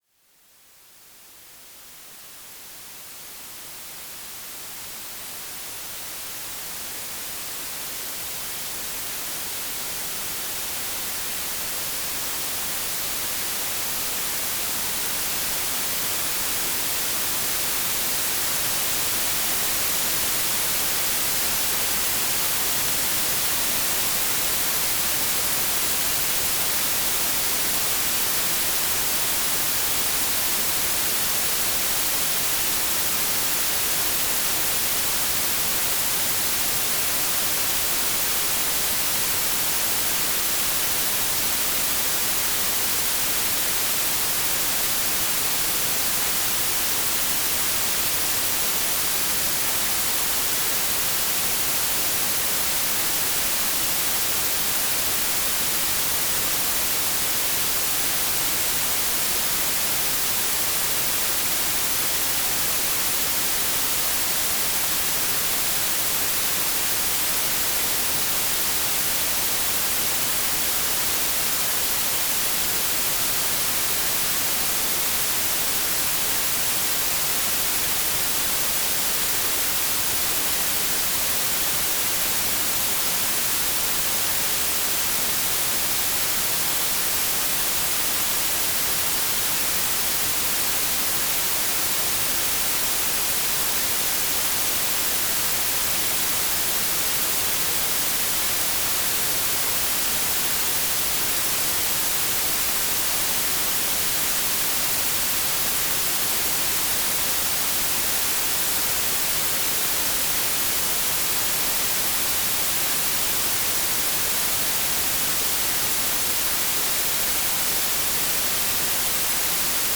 All tracks speed up at the end to bring you to a more alert state. The relaxation ones go down to Alpha and the meditation ones down to Theta.
White Noise - Meditation - 30 min.mp3